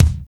KIK RUFFLE K.wav